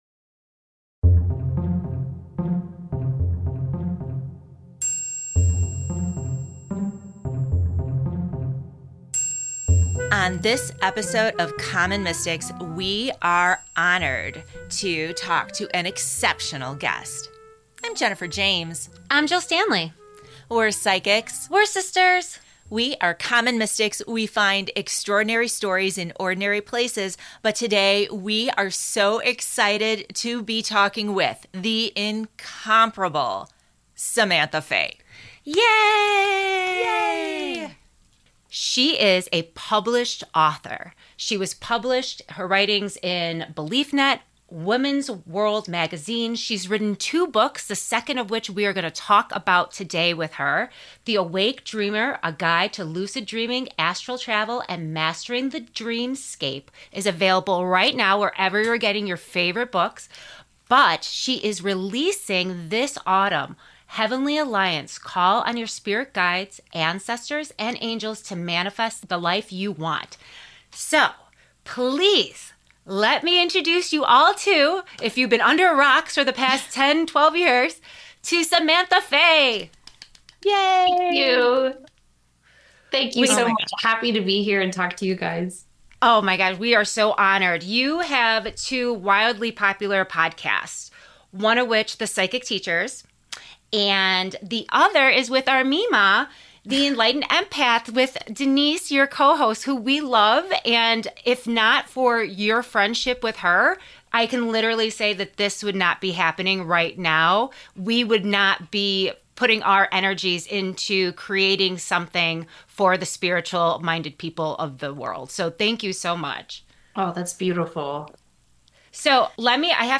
103: Interview